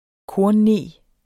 Udtale [ ˈkoɐ̯n- ]